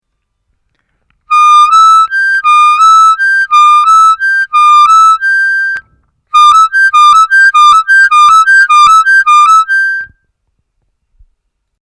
Вертушка +8’+8+9